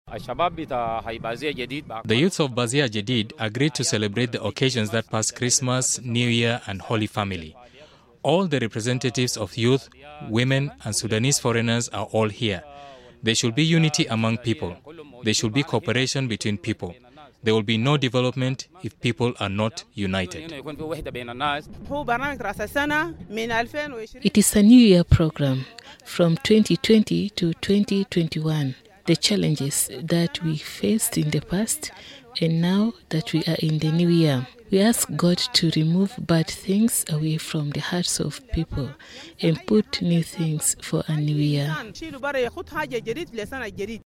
Some of the returnees who spoke to Radio Miraya emphasized the need to put behind challenges faced in 2020 and open a new page of unity and peaceful co-existence.